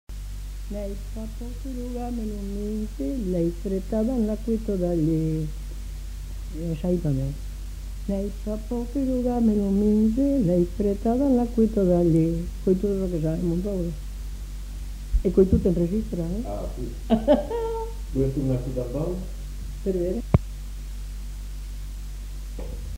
Aire culturelle : Haut-Agenais
Lieu : Cancon
Genre : chant
Effectif : 1
Type de voix : voix de femme
Danse : rondeau